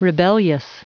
Prononciation audio / Fichier audio de REBELLIOUS en anglais
Prononciation du mot : rebellious